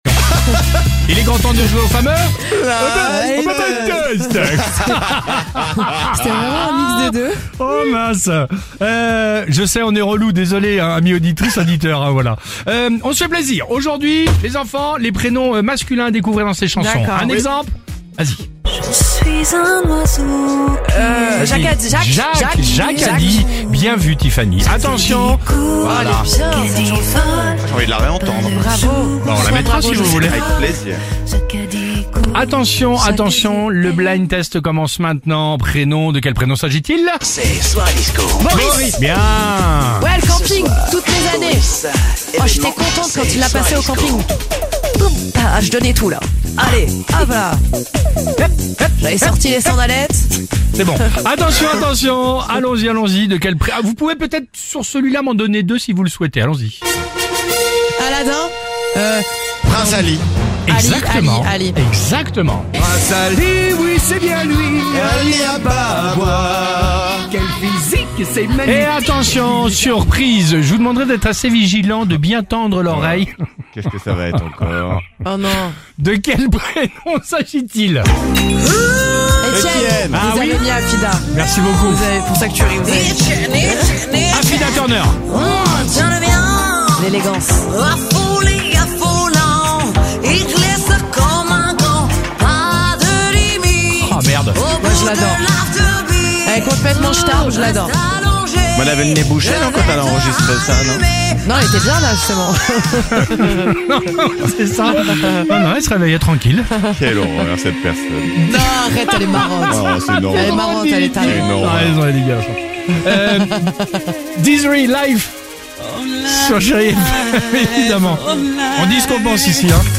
Tous les matins, 7h35 sur Chérie FM, c’est le Blind Test du Réveil Chérie ! Celui de l’équipe qui marque le plus de points gagne !